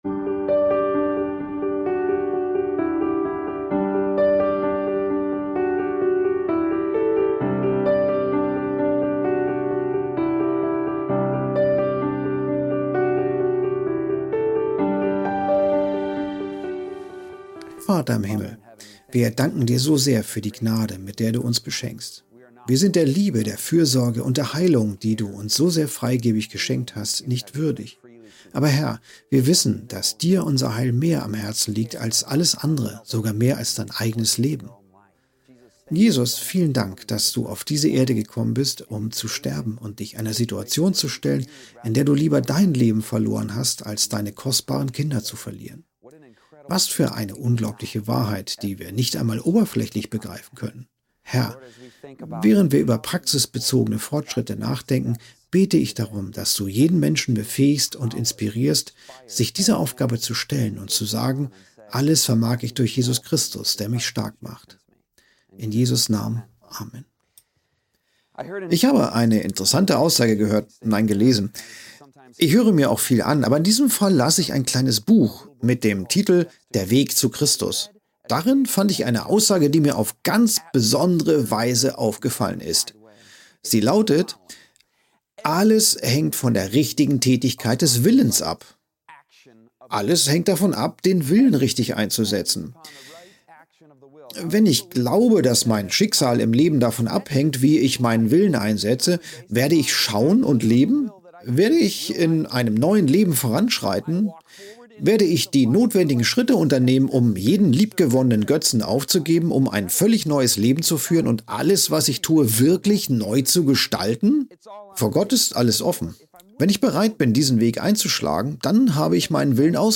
Ein fesselnder Vortrag ermutigt dazu, die Liebe und Kraft Gottes anzunehmen, um aus Schuld und Scham auszubrechen. Mit biblischen Einsichten und erprobten Methoden zeigt er, wie man den eigenen Glauben stärkt, um Sünden abzulegen und das Leben mit Ausdauer zu meistern.